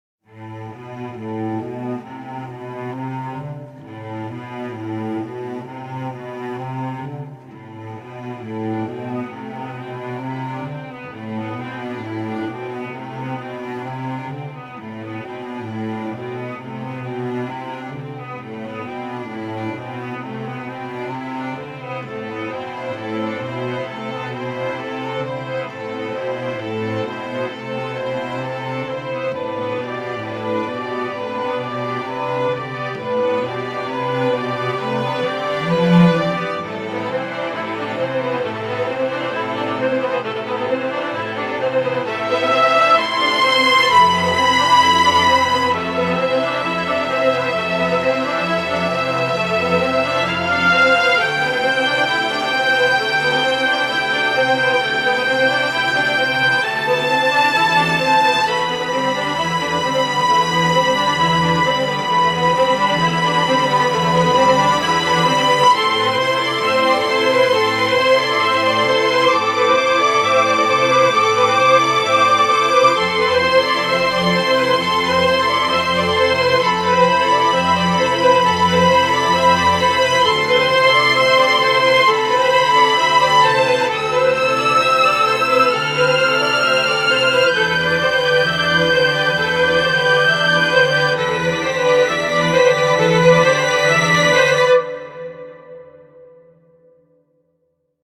tema dizi müziği, duygusal hüzünlü üzgün fon müzik.